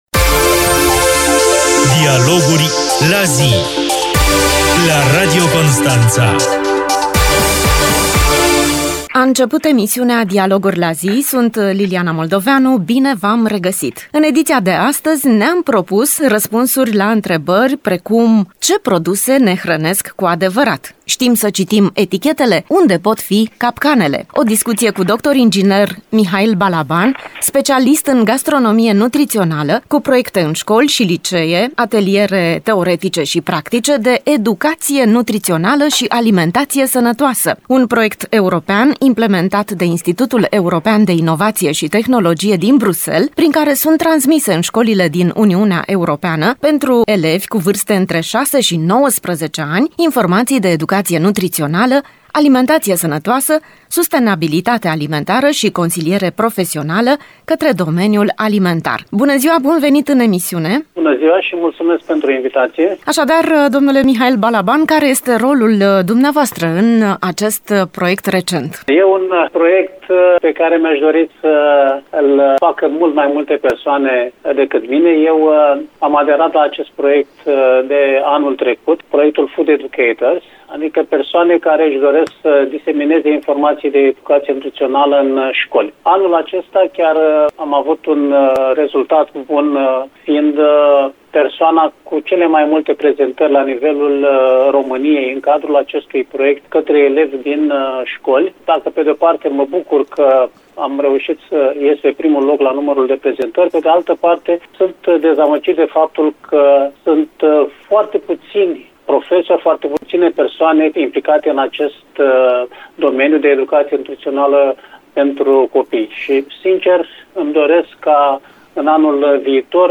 Dialoguri la zi: O discuție despre gastronomie nutrițională cu dr. ing.